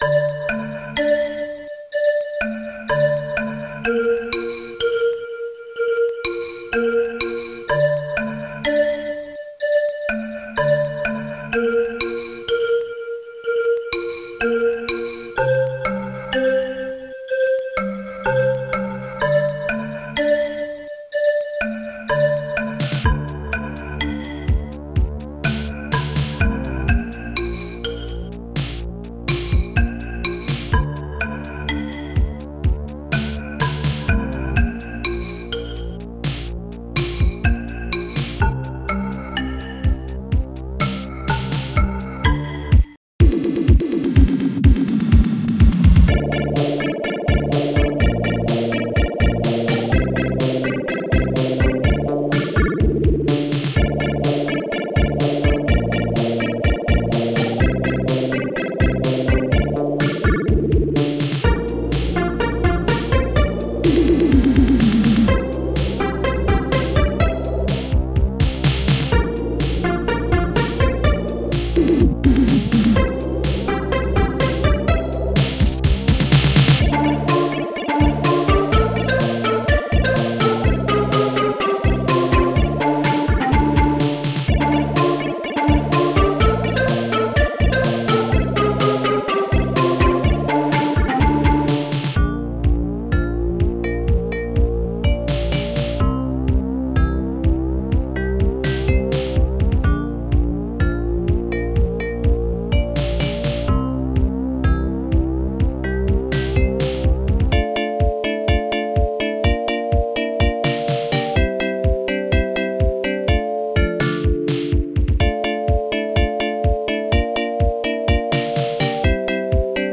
st-01:analogstring
popsnare2
st-03:arztbass
st-01:hihat1
st-01:celeste
st-02:claps3
st-01:distguitar